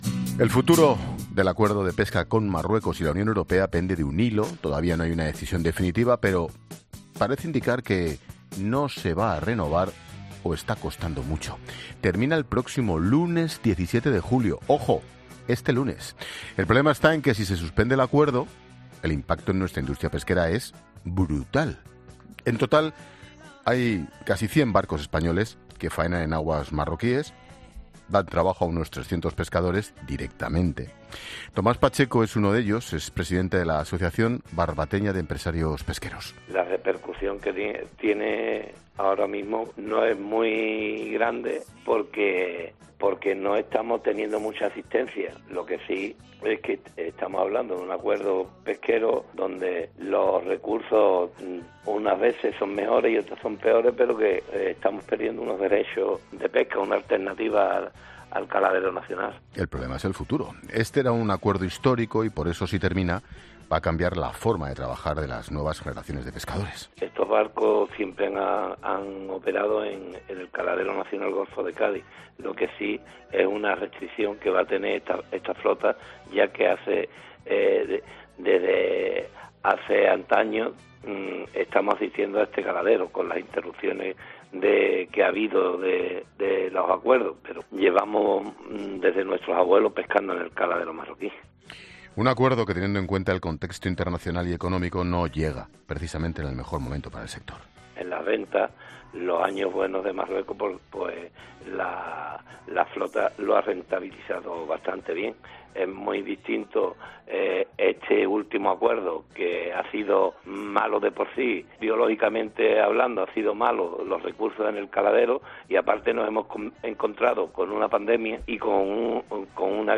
La Linterna analiza mediante expertos del sector pesquero el futuro tras el 17 de julio, día en que concluye el plazo para renovar el acuerdo comercial